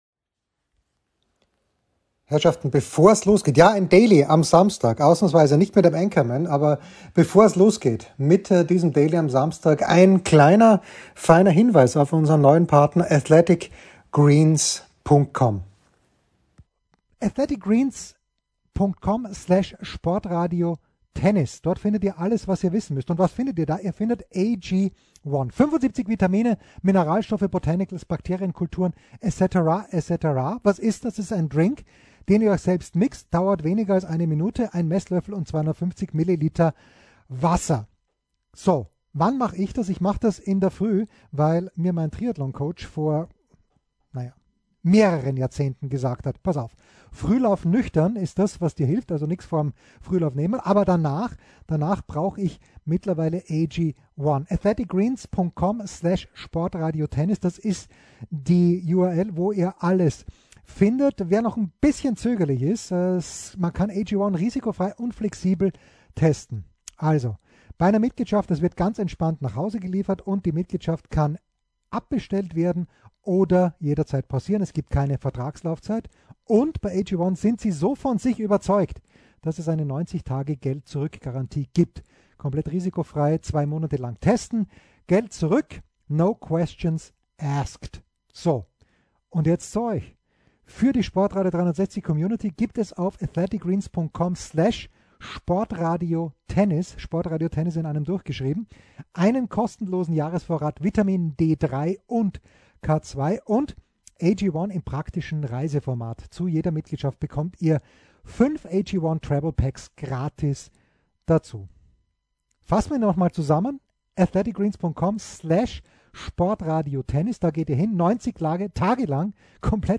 Nugget, Episode 1.496, 07.01.2023: Warum nicht das Jahr mit einem Gespräch mit Starcoach Günter Bresnik beginnen?